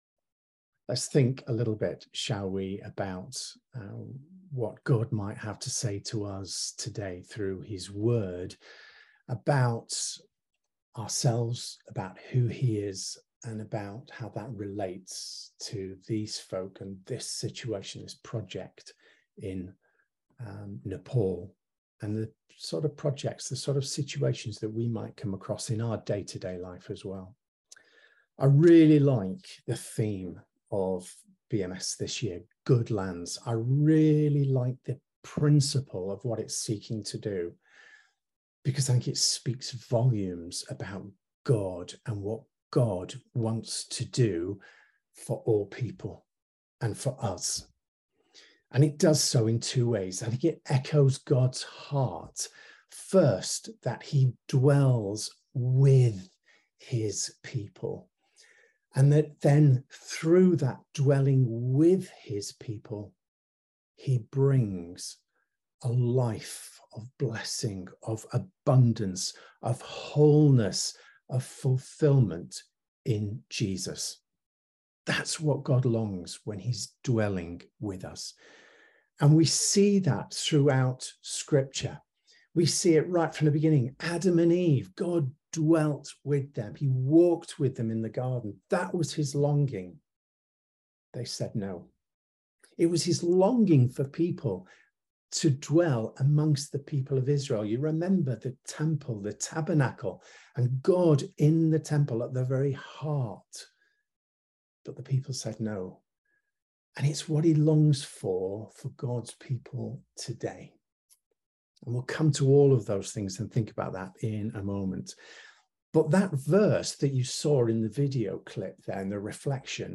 Harvest service – ‘Good Land’